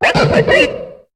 Cri de Baggaïd dans Pokémon HOME.